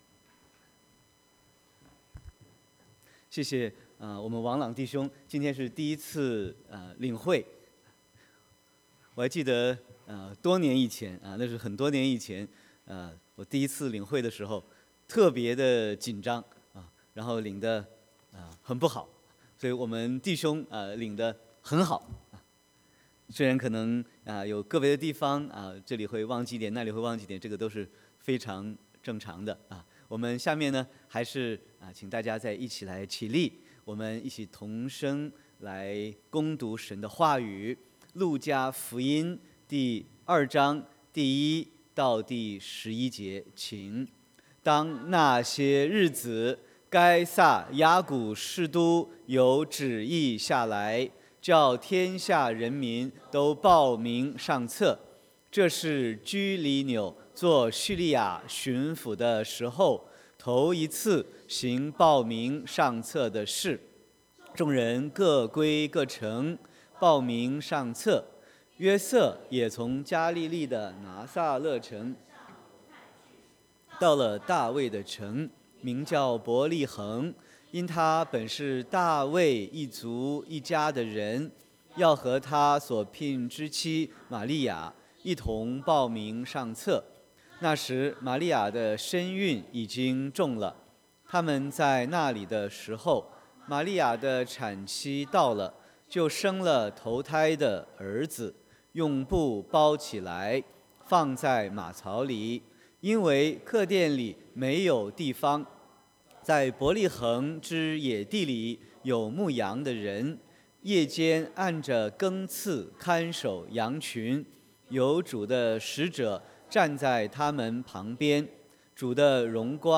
Sermon 12/16/2018